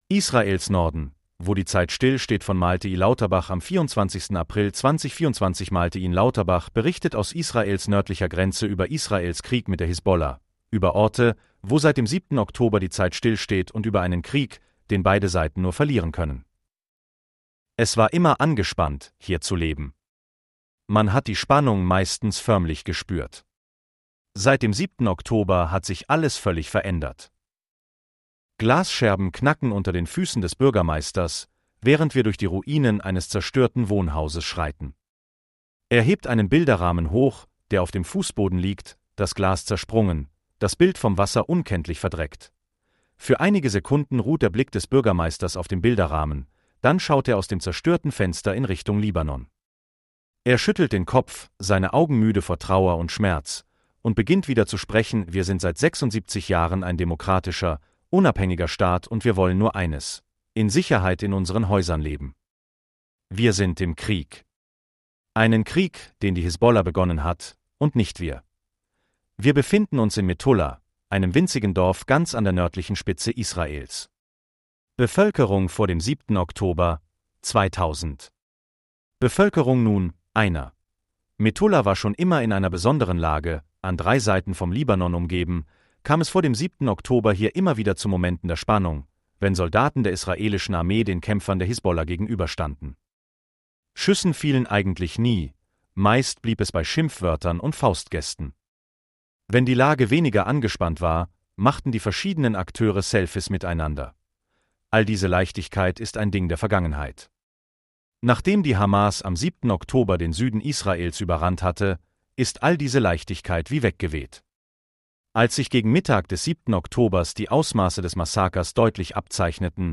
berichtet aus Israels nördlicher Grenze